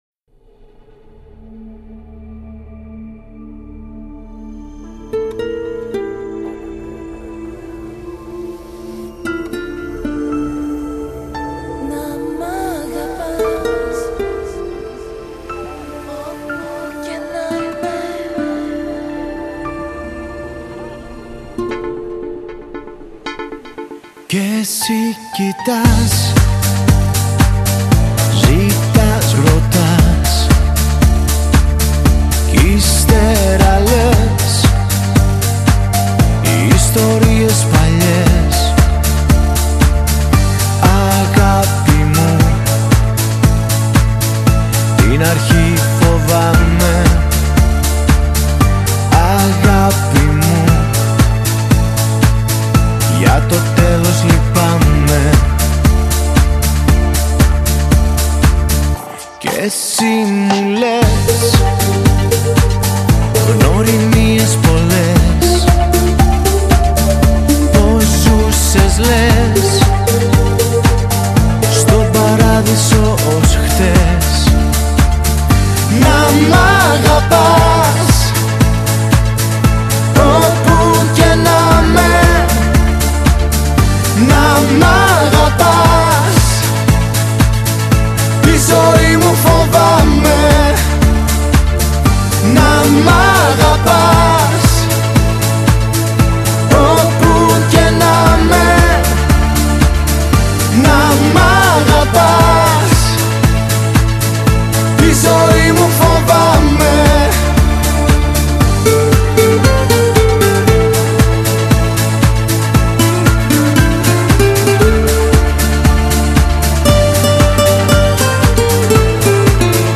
Вот песня с "плавной" концовкой: